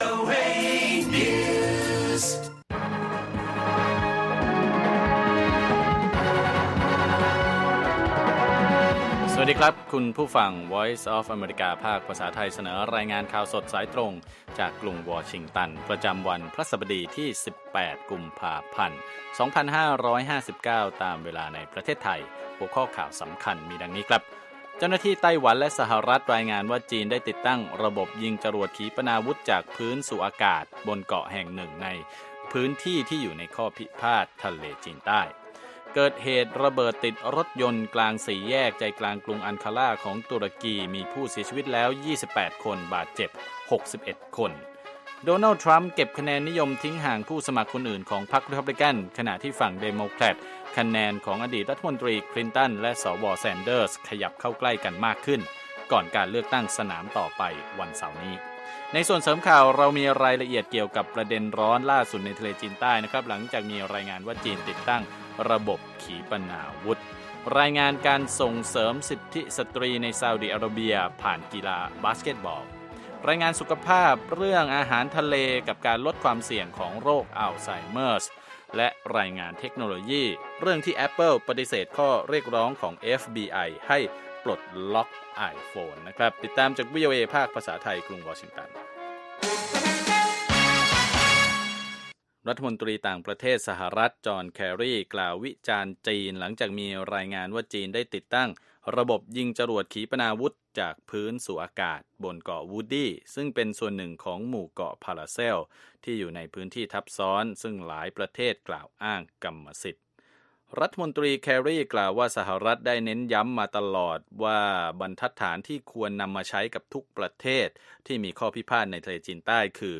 ประมวลข่าวสำคัญล่าสุดของวัน รายงานธุรกิจ บทวิเคราะห์ทางการเมือง รายงานวิทยาศาสตร์และเทคโนโลยีการแพทย์ เรื่องของสตรี การศึกษาและสังคม รายงานการบันเทิงและวัฒนธรรมอเมริกัน รวมทั้งชีวิตคนไทยในอเมริกา